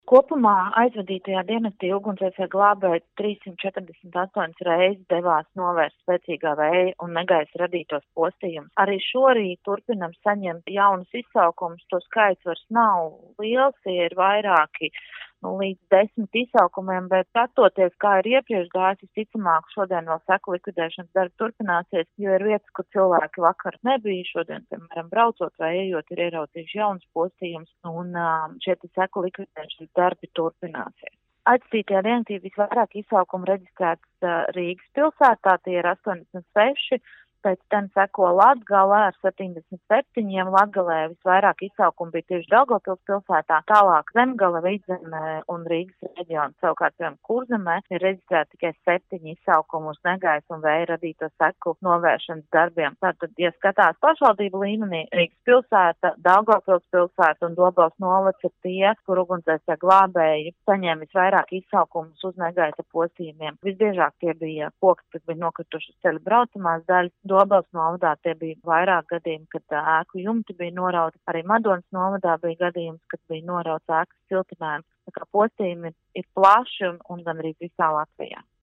RADIO SKONTO Ziņās par vētras nodarīto postu aizvadītajā diennaktī